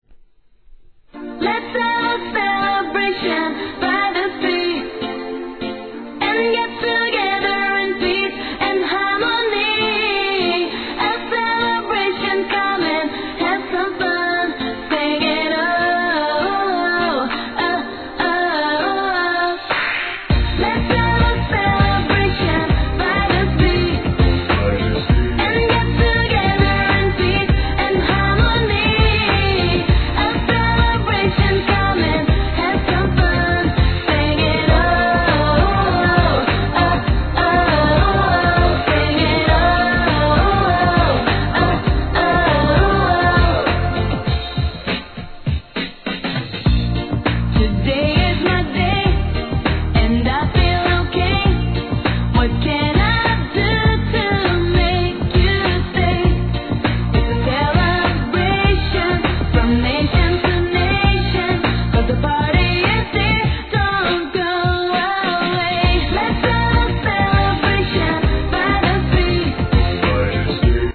HIP HOP/R&B
レゲエ調のギターリフが気持ちイ〜トラックにポップスな歌声で大ヒット